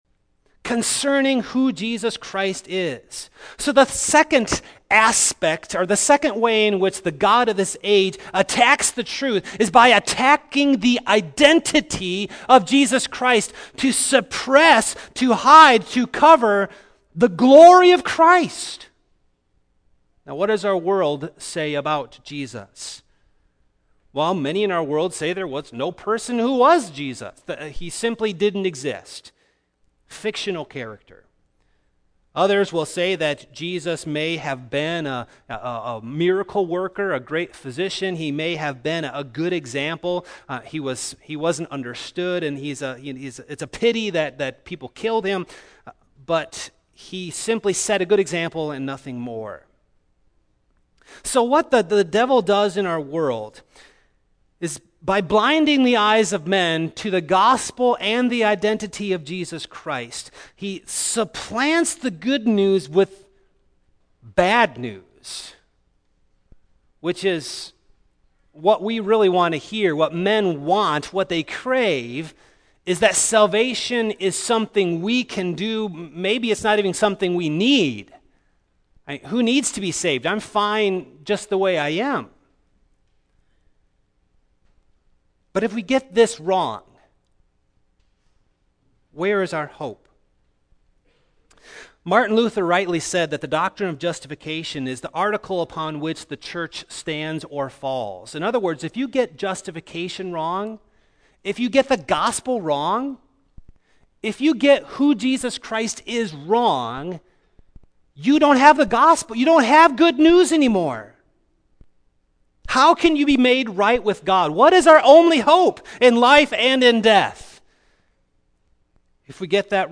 After Darkness, Light! (not the full sermon)